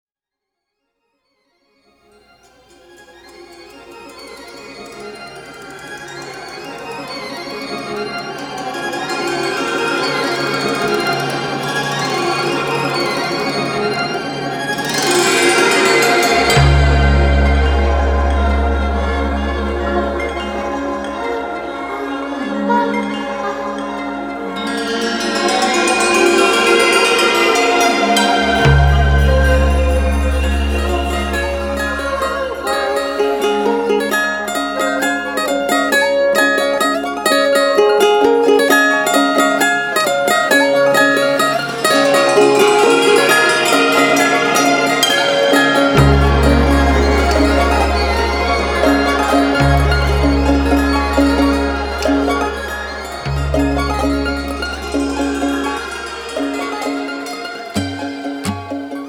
Genre: Indie Pop, Orchestral Pop